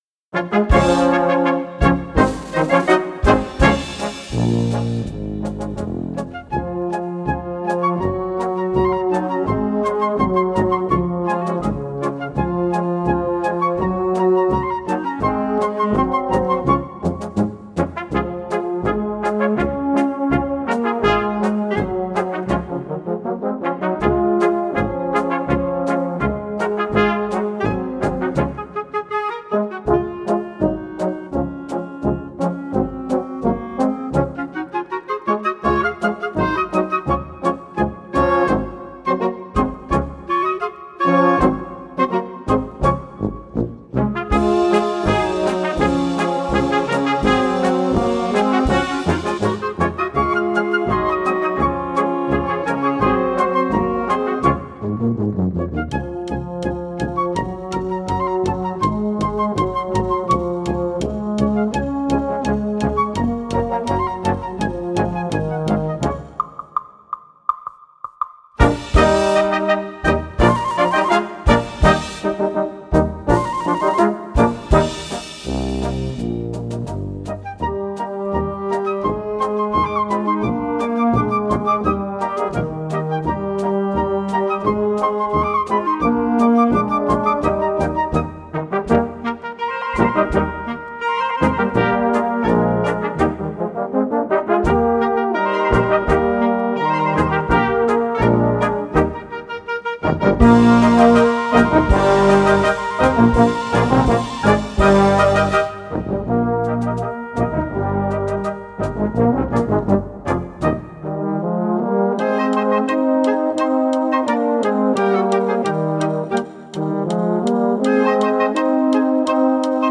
Gattung: Solistische Polka nach einer Volksweise
Besetzung: Blasorchester